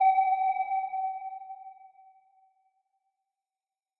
sonarPing.ogg